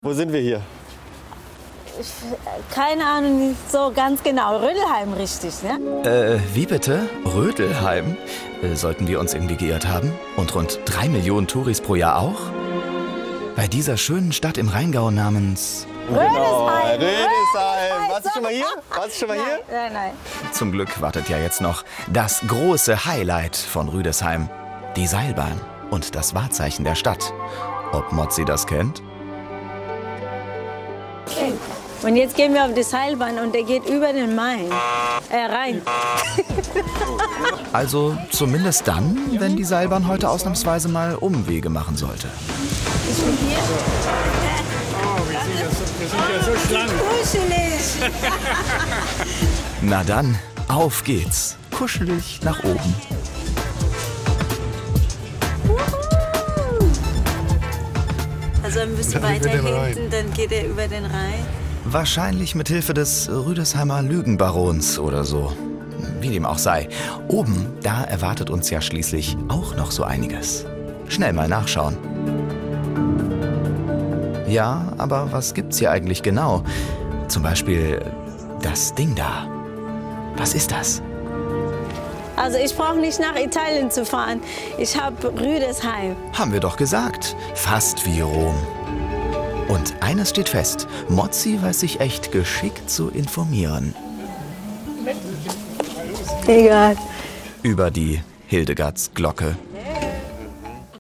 sehr variabel, markant, hell, fein, zart, plakativ
Hörbuch - Demo
Audiobook (Hörbuch)